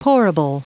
Prononciation du mot pourable en anglais (fichier audio)
Prononciation du mot : pourable